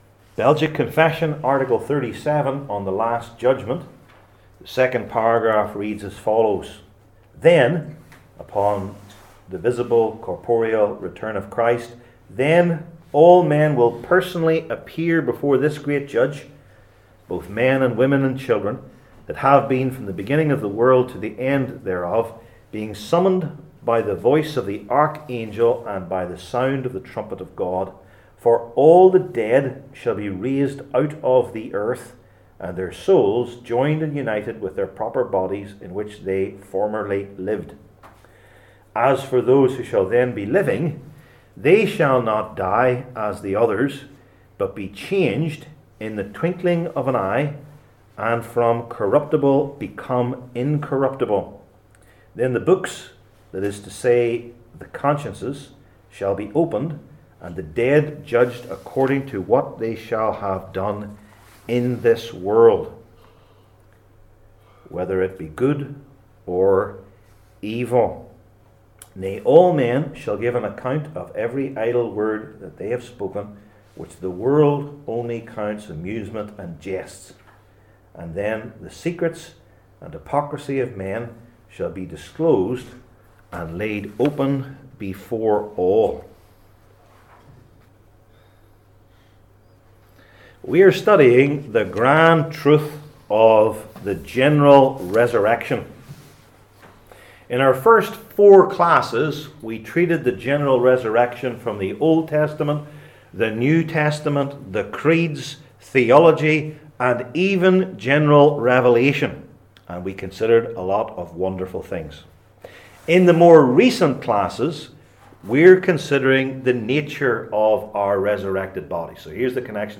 Passage: I Corinthians 15:35-49 Service Type: Belgic Confession Classes THE LAST JUDGMENT …